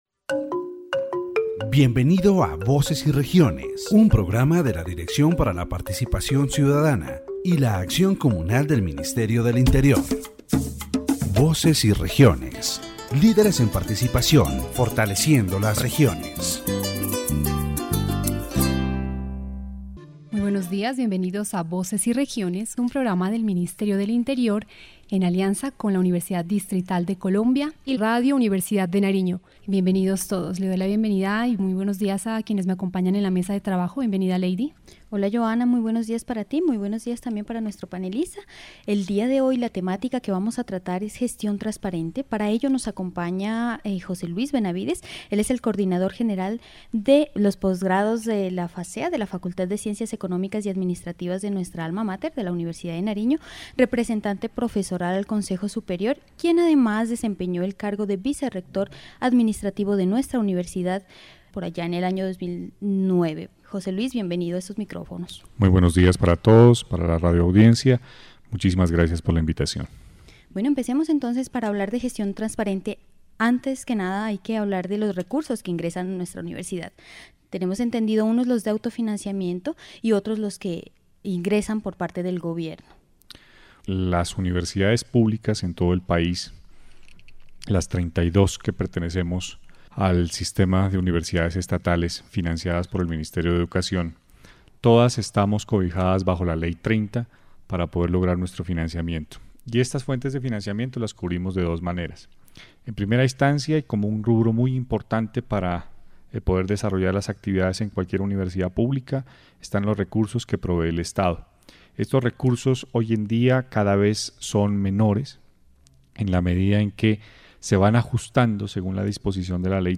The radio program analyzes the complexities of managing a public university, using the University of Nariño as a case study. The debate highlights the challenges universities face in balancing government funding with self-generated income through tuition fees, research grants and other sources.